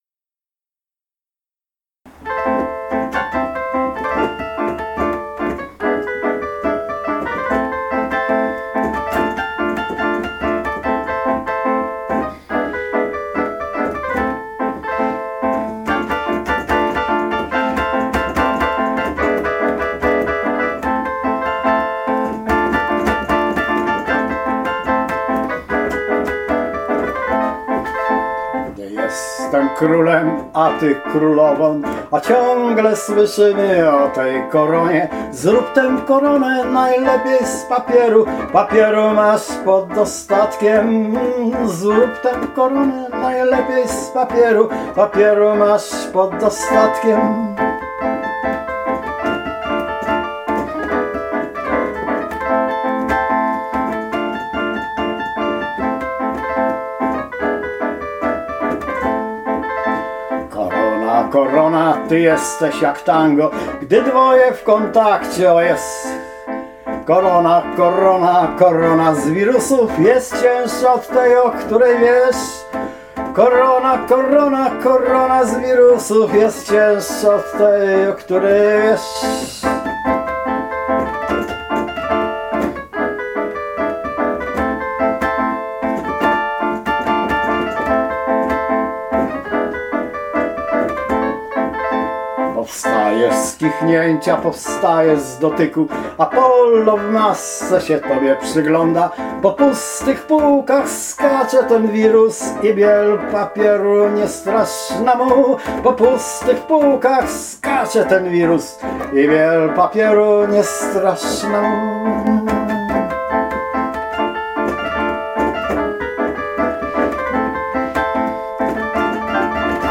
Na zakończenie już na lżejszą nutkę - "piosenka o koronie, która niestety nie wchodzi w poczet królów polskich".
piosenka